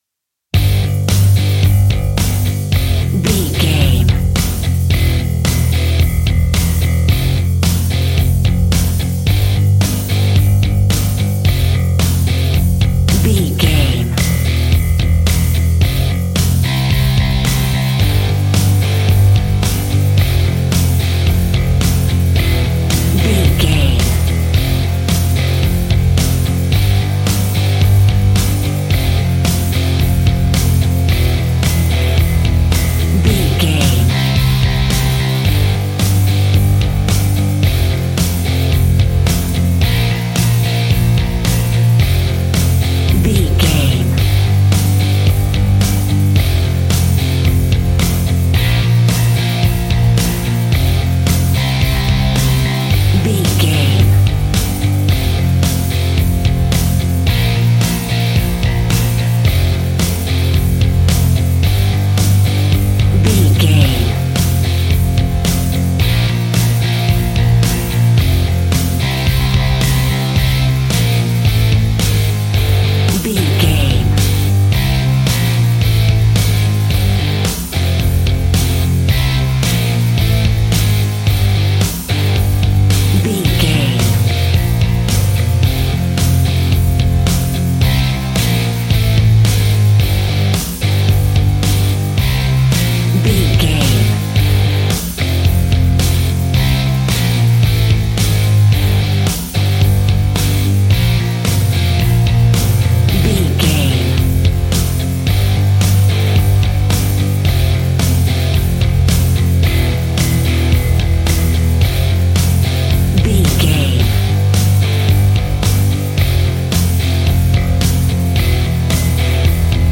Epic / Action
Aeolian/Minor
hard rock
blues rock
distortion
Rock Bass
heavy drums
distorted guitars
hammond organ